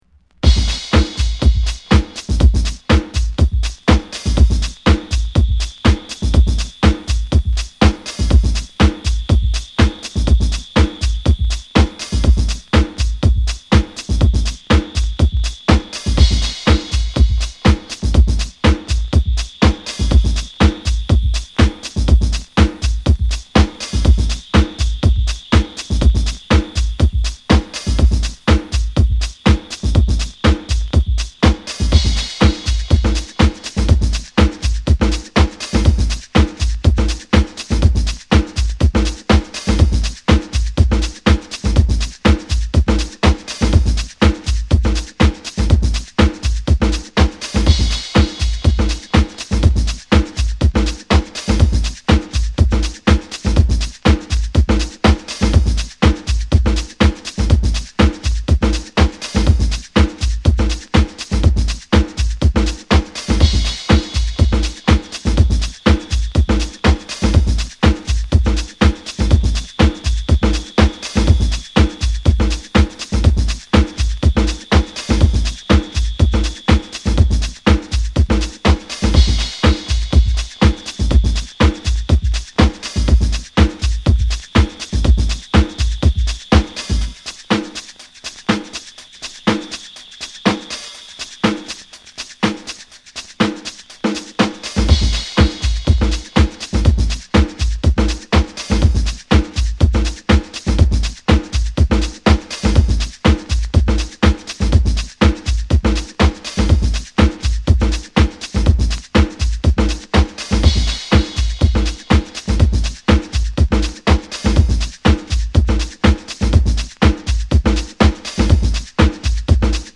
クラブ、ダンス